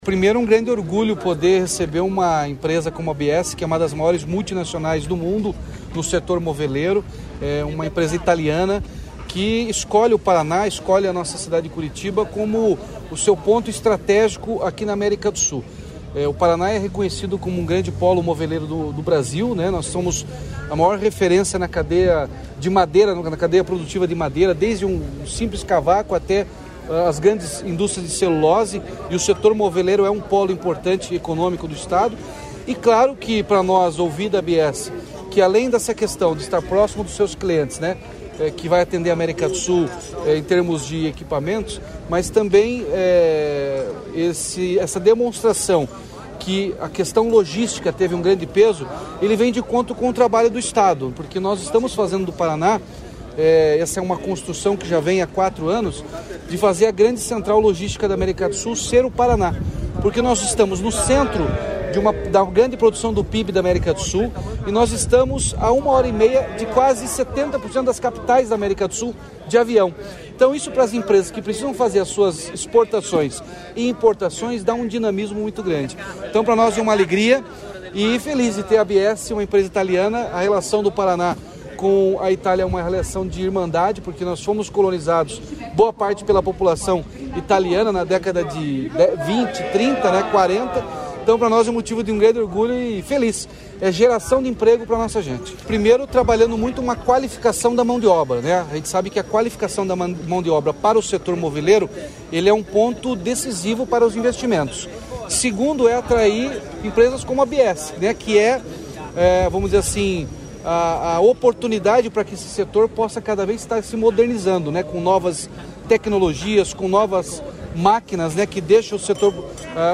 Sonora do governador Ratinho Junior sobre a implantação da filial da marca italiana moveleira Biesse em Curitiba